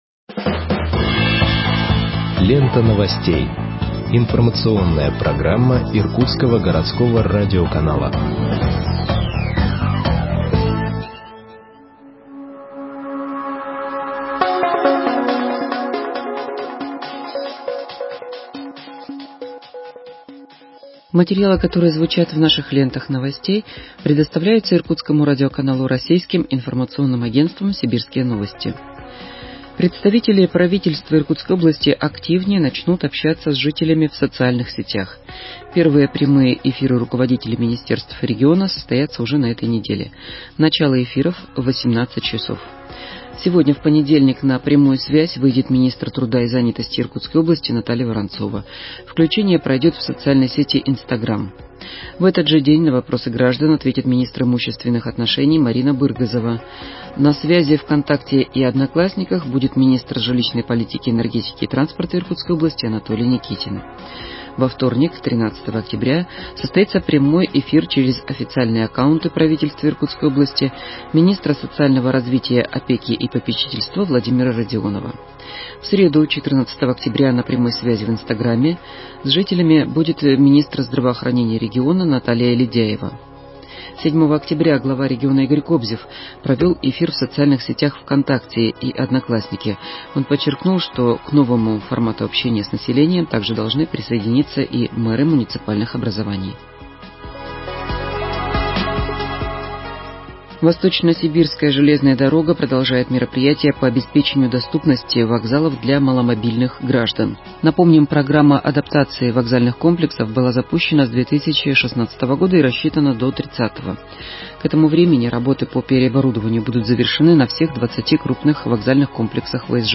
Выпуск новостей в подкастах газеты Иркутск от 09.10.2020